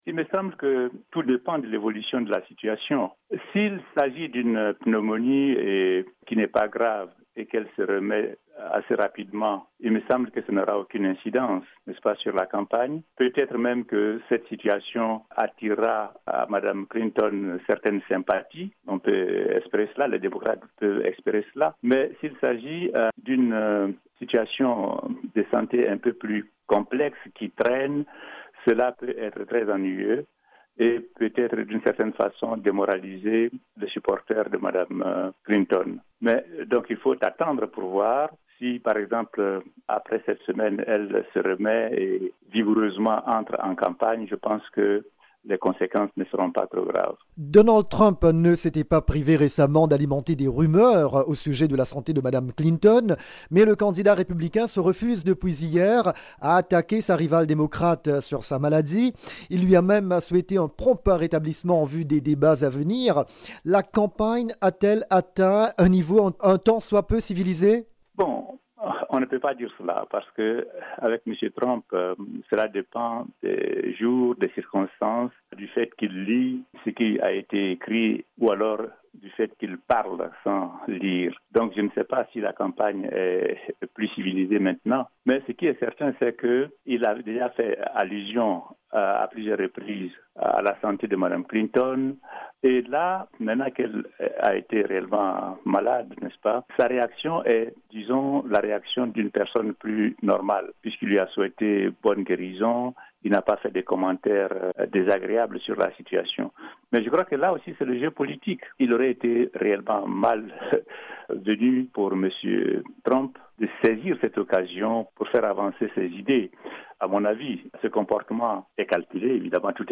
La candidate démocrate à la présidentielle américaine a décidé d’interrompre sa campagne pour se remettre de la pneumonie dont elle souffre. Interview